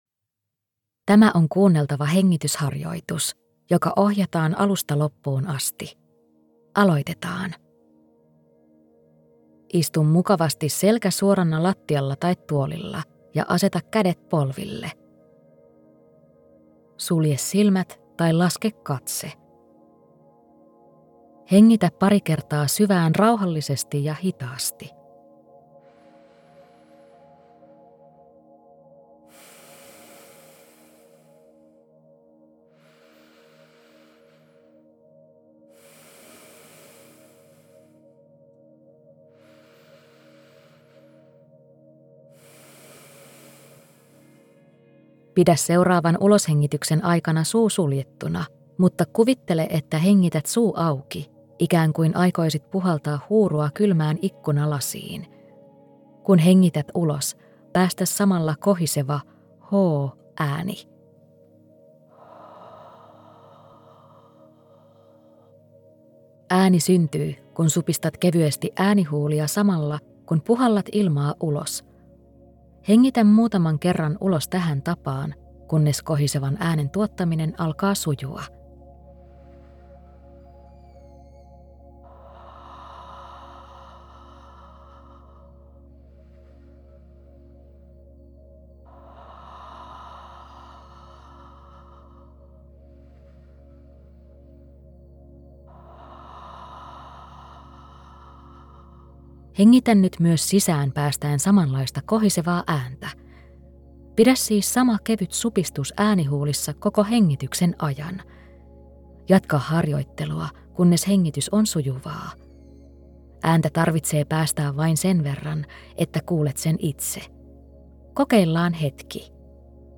Voitokas hengitys – kuunneltava hengitysharjoitus
Tämän harjoitteen hengitystekniikka tuottaa ääntä, mikä edistää keskittymistä.
• Äänite alkaa johdatuksella hengitysharjoituksen tekemiseen.